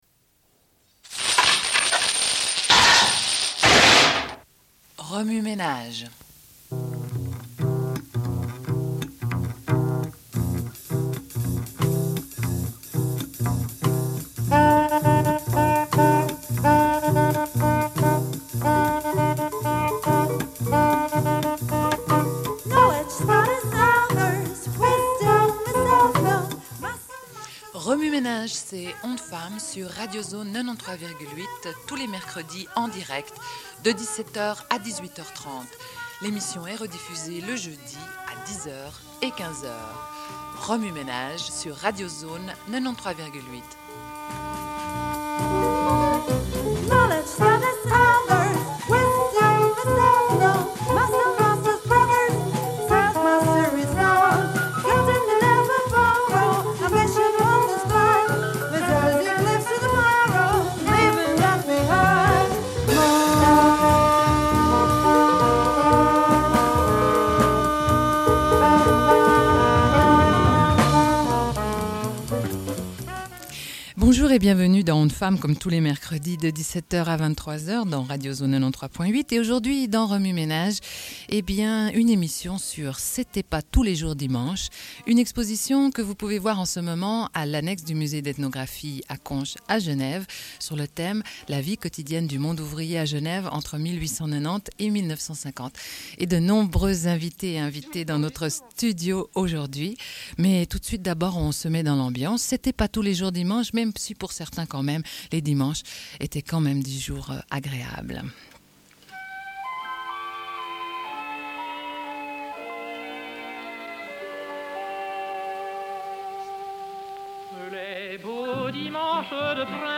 Une cassette audio, face A31:33
Radio Enregistrement sonore